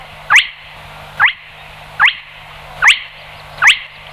Marouette ponctuée
Porzana porzana
marouette.mp3